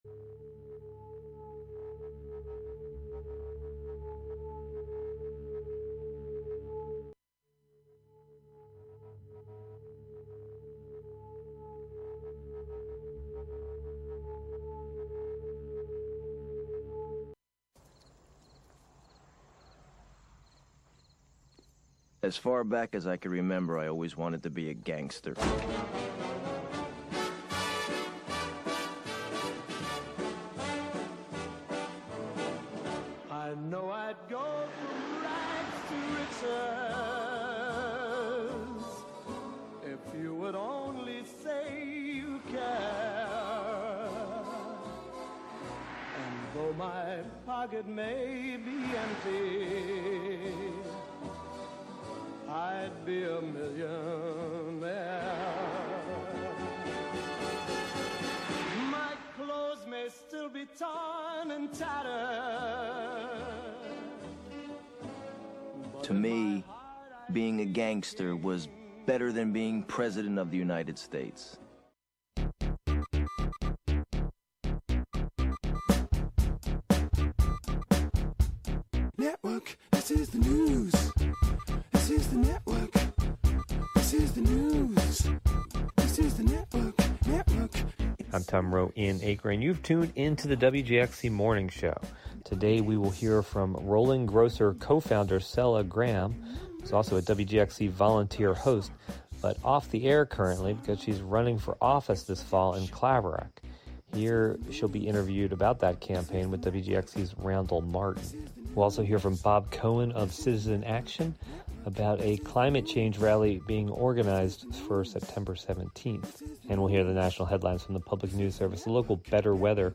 radio magazine show
The show begins each day with local headlines, weather, and previews of community events.